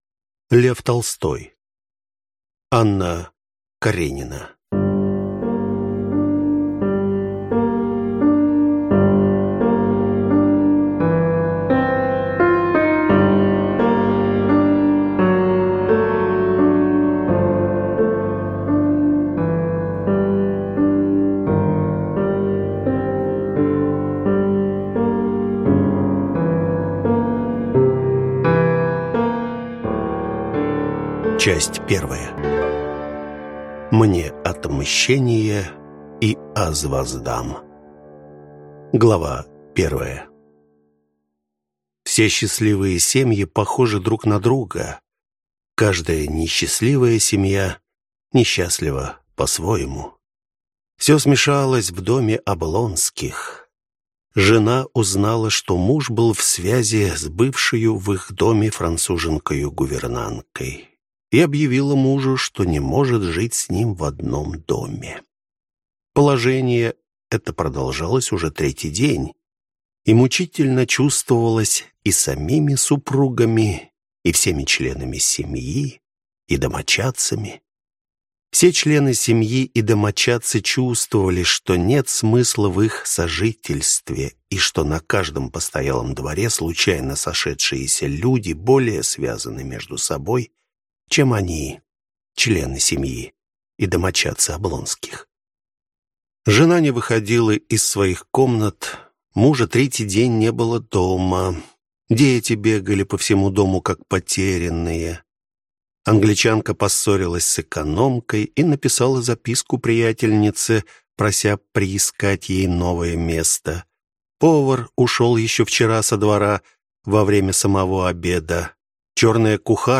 Аудиокнига Анна Каренина | Библиотека аудиокниг